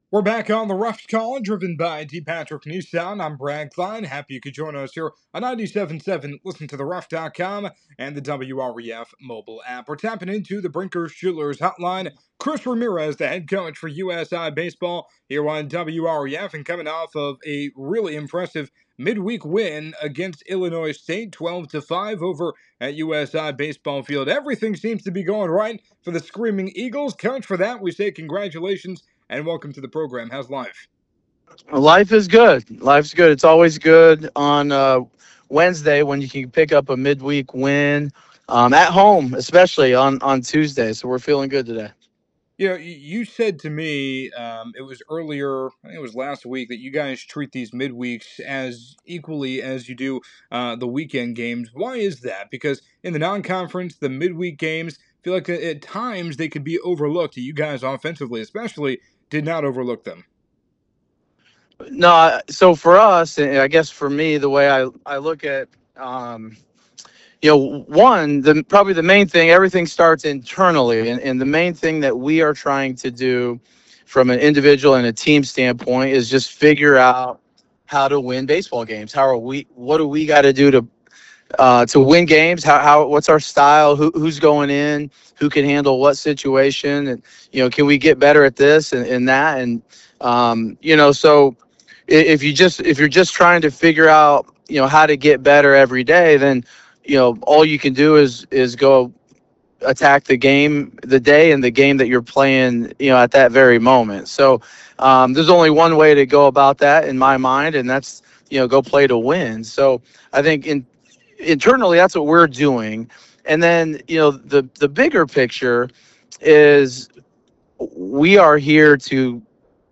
USI Baseball Off to Best Start of DI Era (Interview)